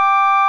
Index of /90_sSampleCDs/E-MU Formula 4000 Series Vol. 3 – Analog Odyssey/Default Folder/Electric Organs